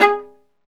Index of /90_sSampleCDs/Roland - String Master Series/STR_Viola Solo/STR_Vla Marcato